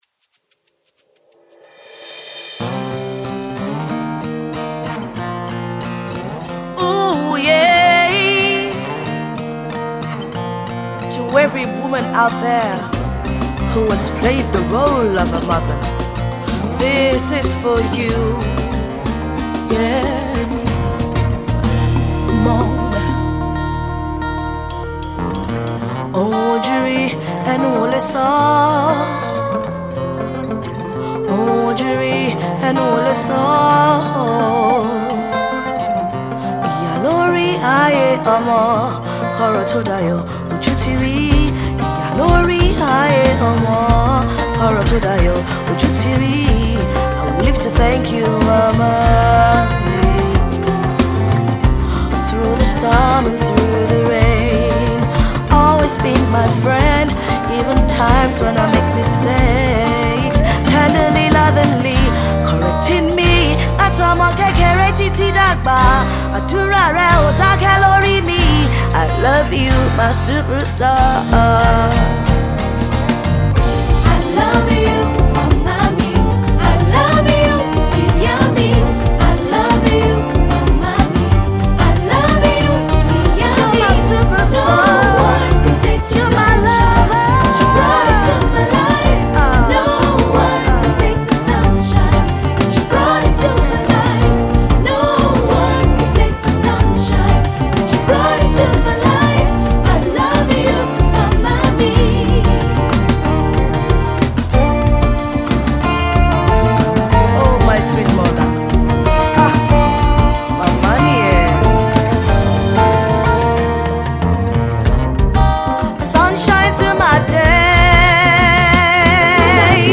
Gospel Highlife Music